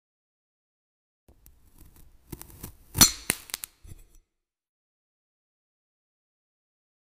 Guess what fruit is inside sound effects free download